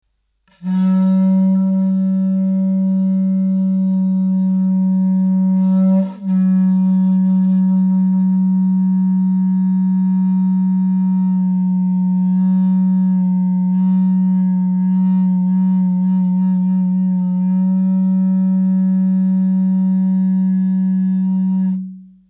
ホラチク （竹）
竹の節にあいている穴が吹き口　唇をぴったり穴に押し当て　息がもれないように吹きます